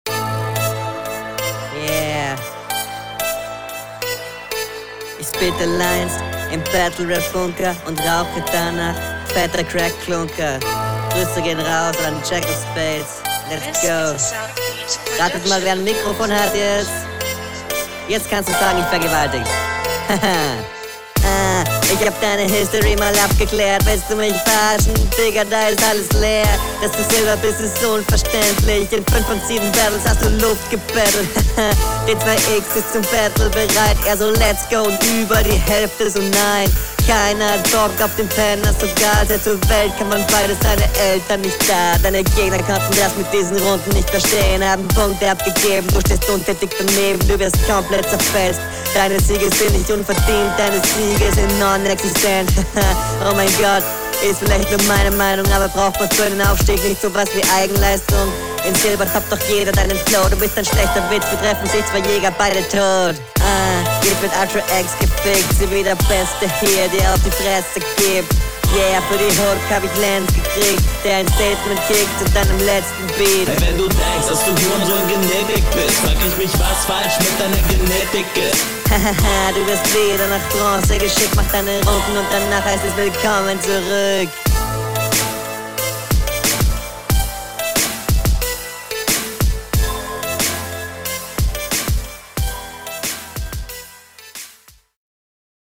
Flow stabil, Mix ist ass, Punches sind schön auf ein Thema konzentriert, Reime sind ganz …
Audio bisschen besser aber nicht viel.
Sorry aber der Stimmenverzerrer zerstört meiner Meinung nach den ganzen Vibe